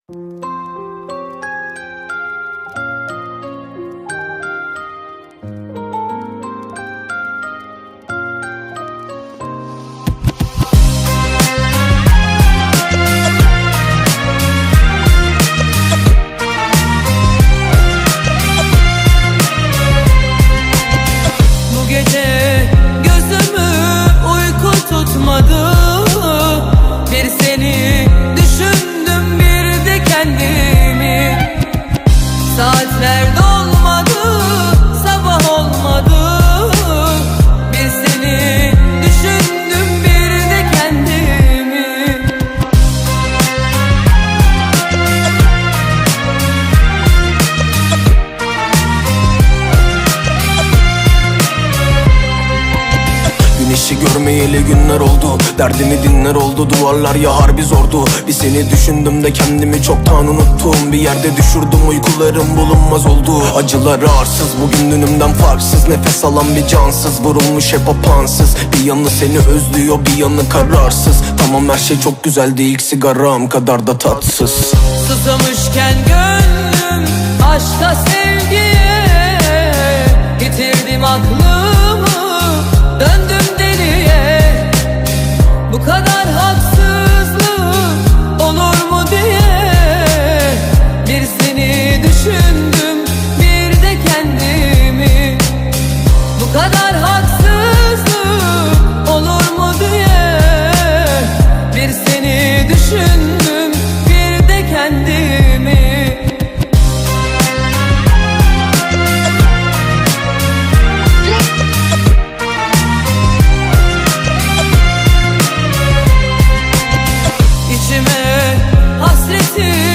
Качество: 320 kbps, stereo
Турецкие песни